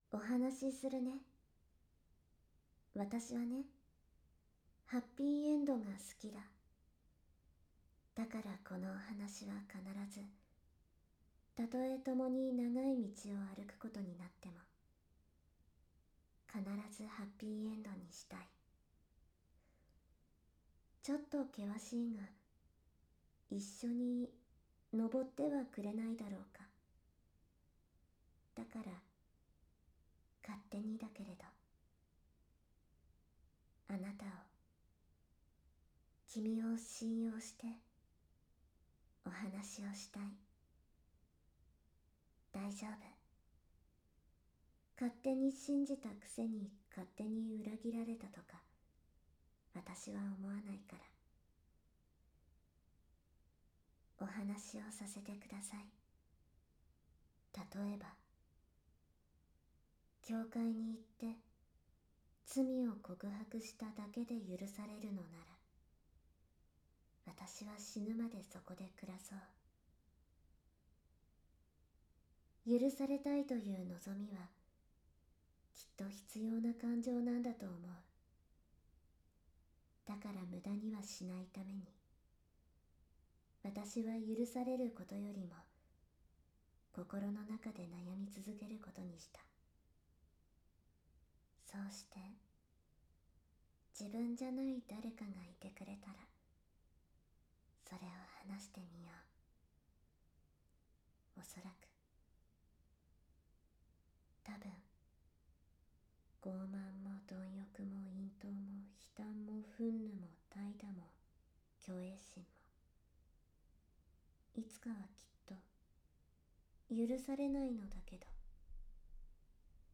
Dolby-digital 5.1ch おはなしするね.wav
Dolby-digital 5.1ch おはなしするね.m4a